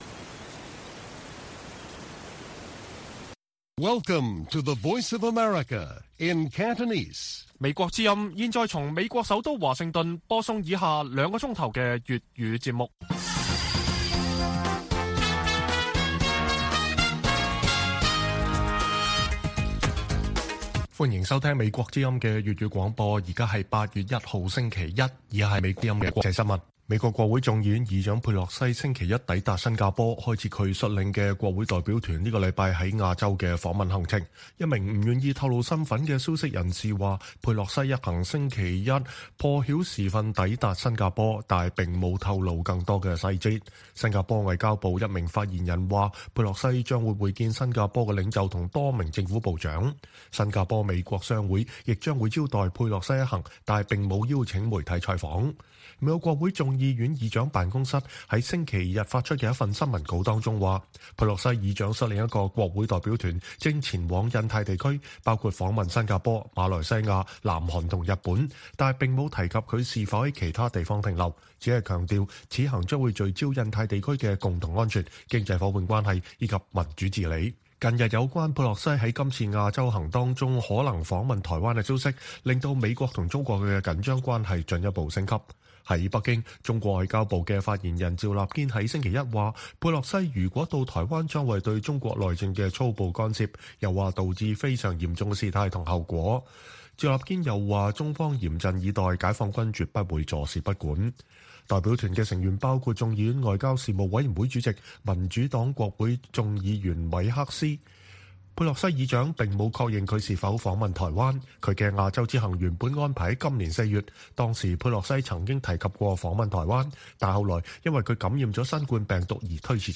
粵語新聞 晚上9-10點: 港府工作小組首開會調查Mirror演唱會嚴重事故